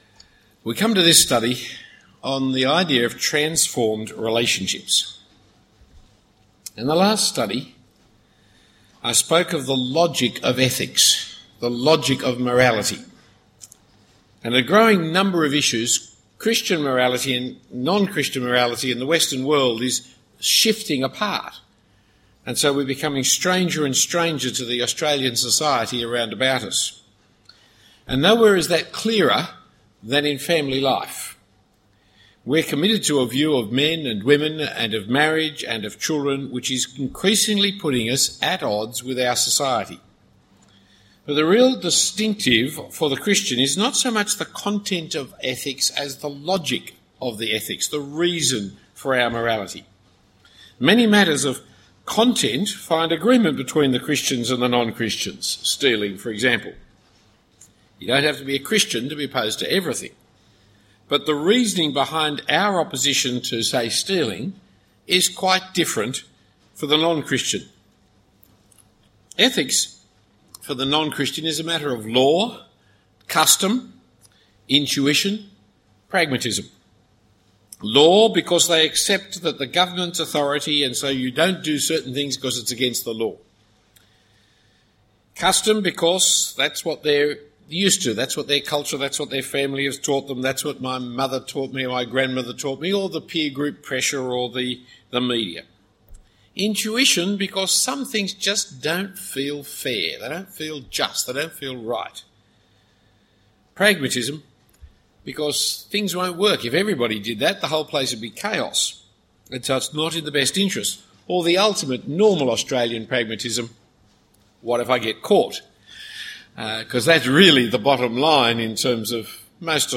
This series was given at St Andrews Cathedral in 2009.